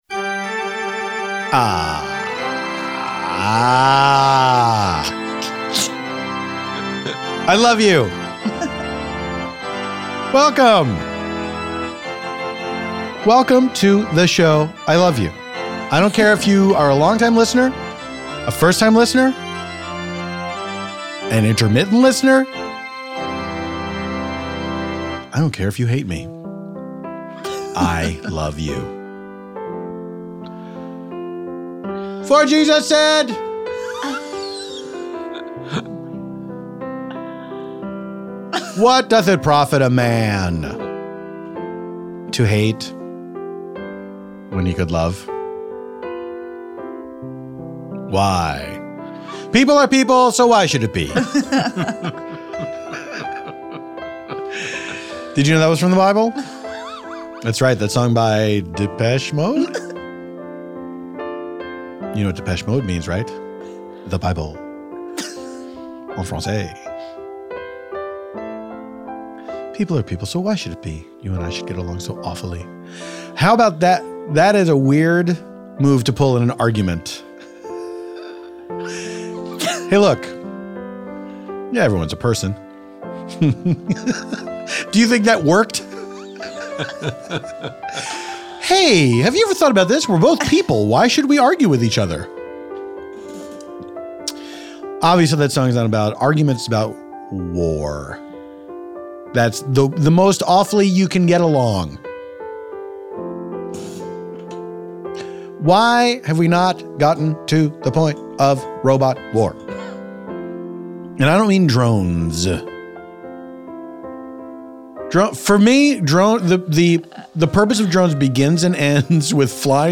Jameela chats about where she plans to spend the apocalypse, Target being her favorite place on Earth, and the 3 times she broke her nose. Then, they are joined by improvisers & The Good Place all-stars Maribeth Monroe and Marc Evan Jackson, to improvise a story set in a Target.